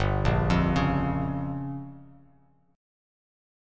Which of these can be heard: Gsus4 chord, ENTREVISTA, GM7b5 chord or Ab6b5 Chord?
GM7b5 chord